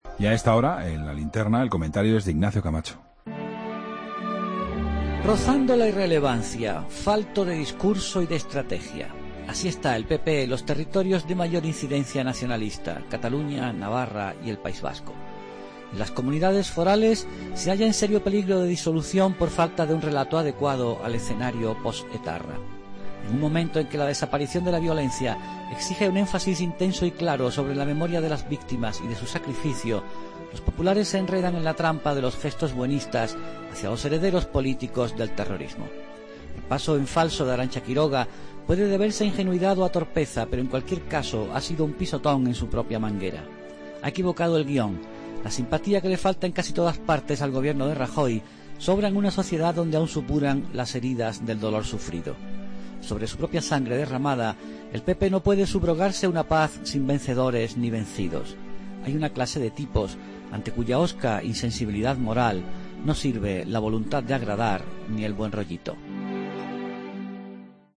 Comentario de Ignacio Camacho, en La Linterna